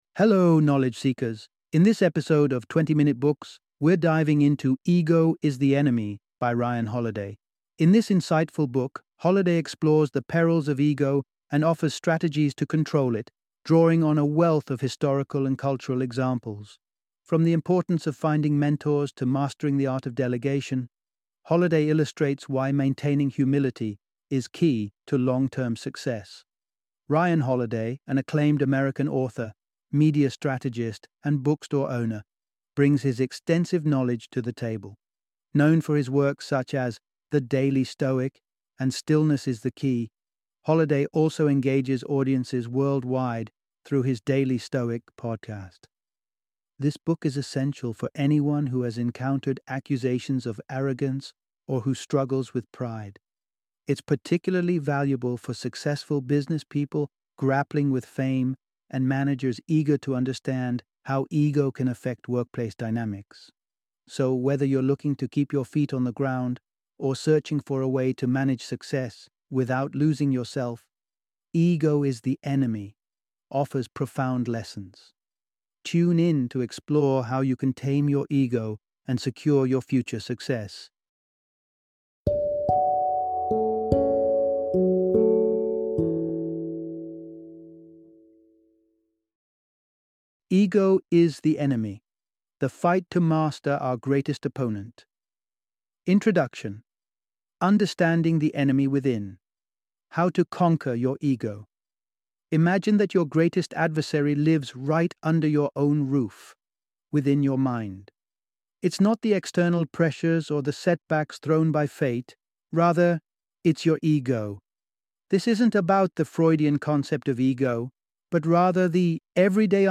Ego is the Enemy - Audiobook Summary